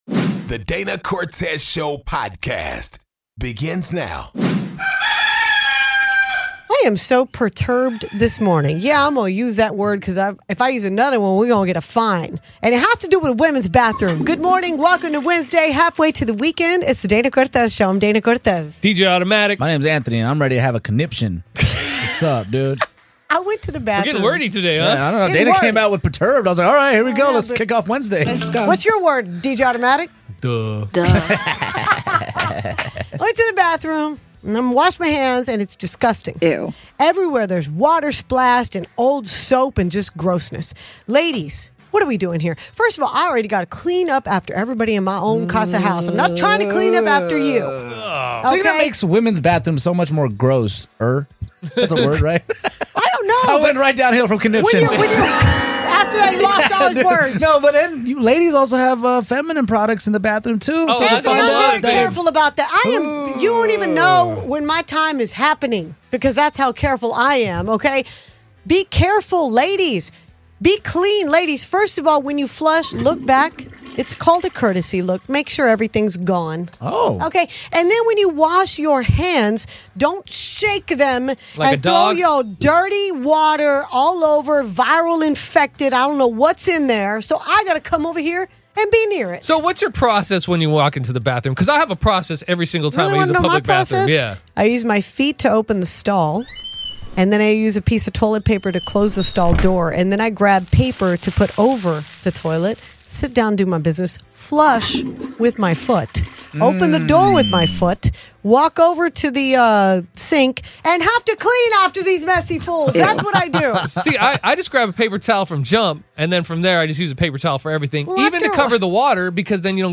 DCS Interviews Boxing Icon Oscar De La Hoya